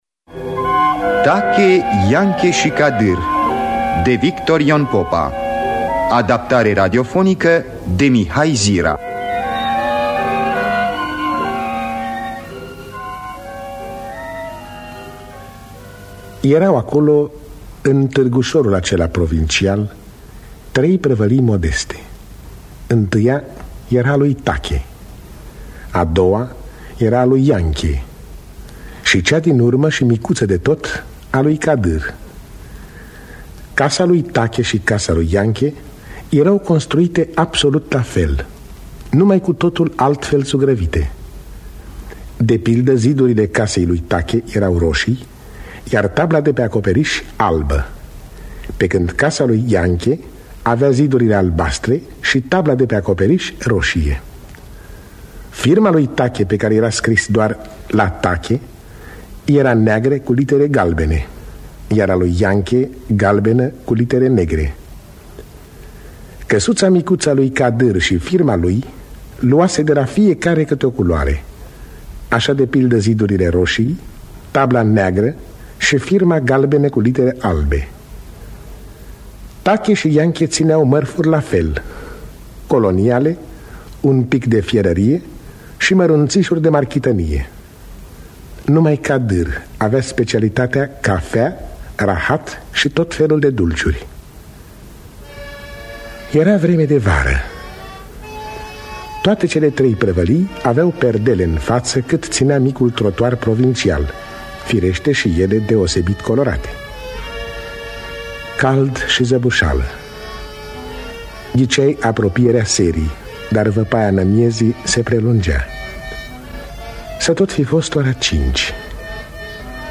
Teatru Radiofonic Online